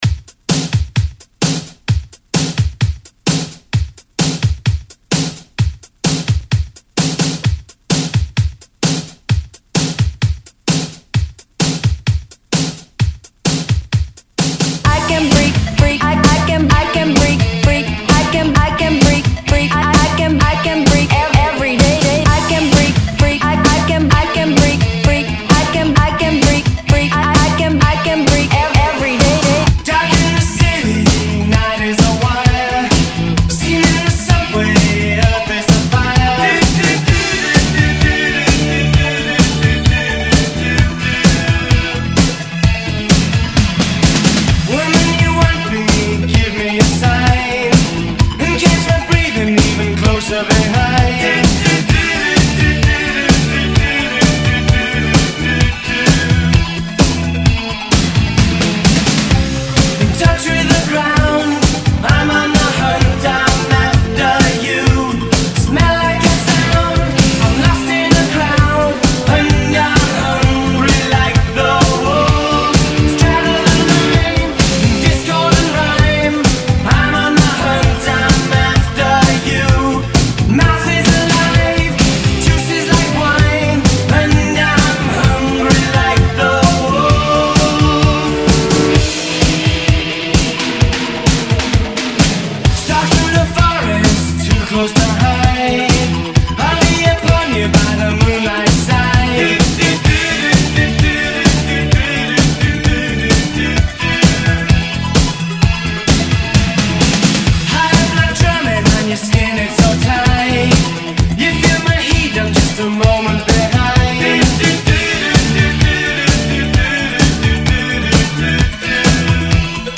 Genre: 90's
Clean BPM: 136 Time